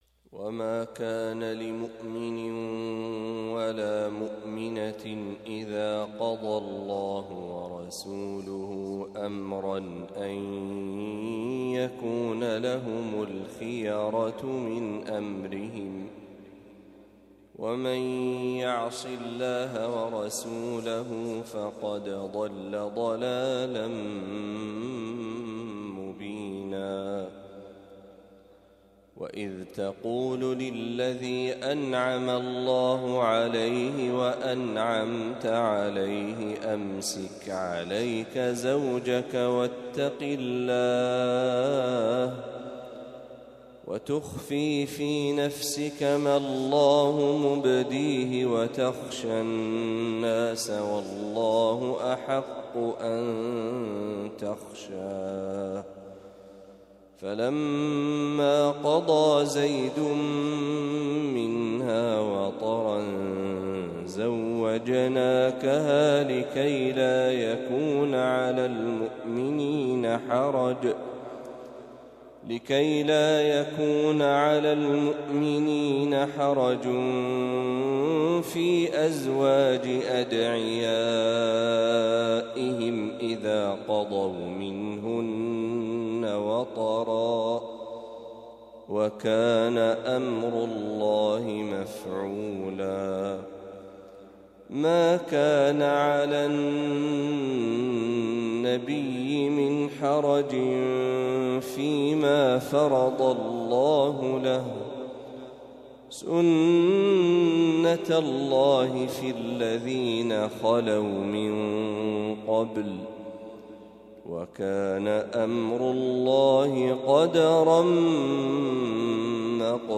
ما تيسر من سورة الأحزاب | فجر الأربعاء ١ ربيع الأول ١٤٤٦هـ > 1446هـ > تلاوات الشيخ محمد برهجي > المزيد - تلاوات الحرمين